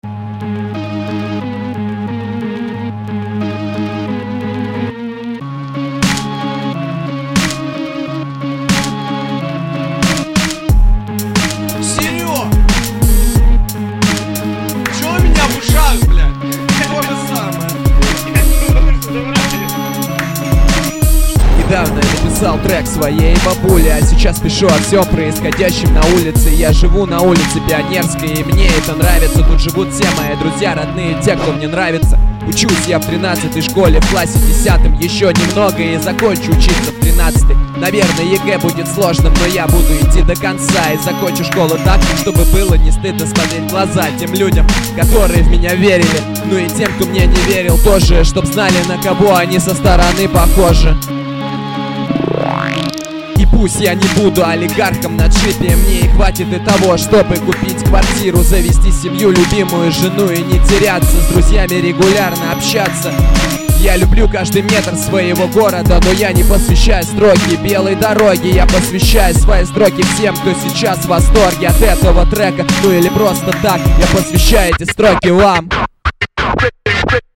Форум российского битбокс портала » Оффтопик » Общалка » Парни, помогите раскрутить группу (На ваш суд представляю рэп группу - Солянка (минуса мои))